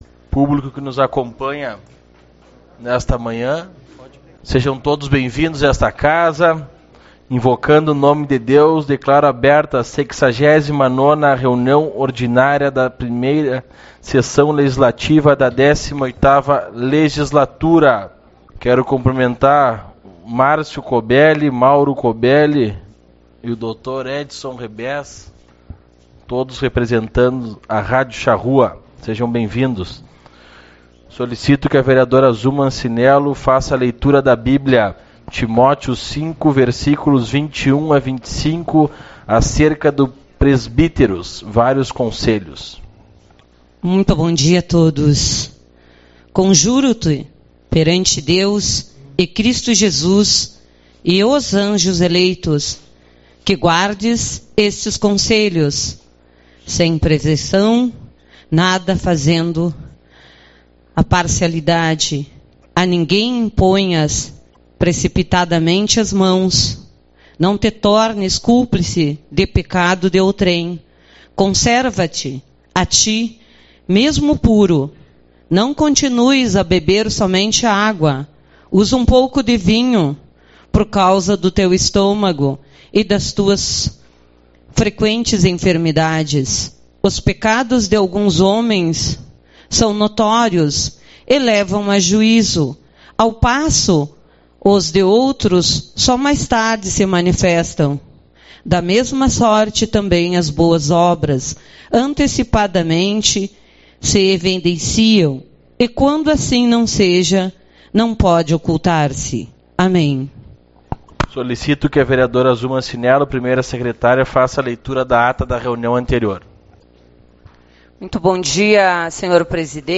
21/10 - Reunião Ordinária